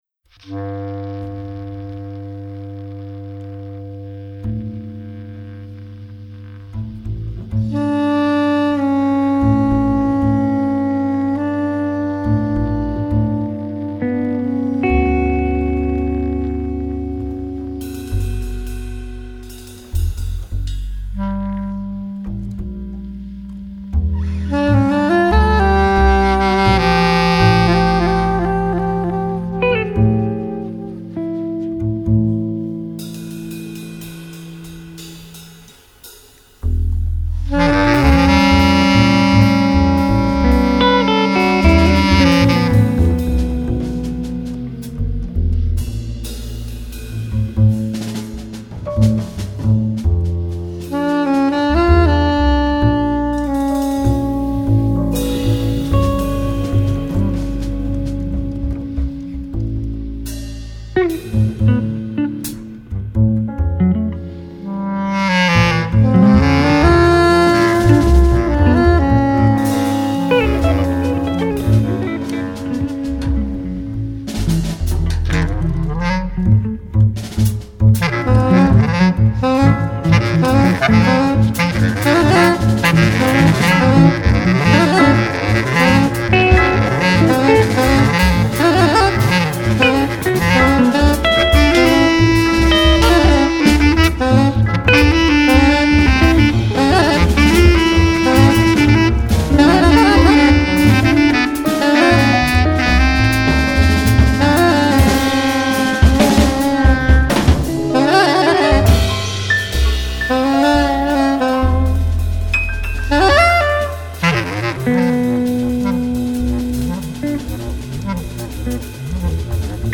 piano
clarinette basse
saxophone soprano
contrebasse. Un quintet très actif sur la côte d’azur.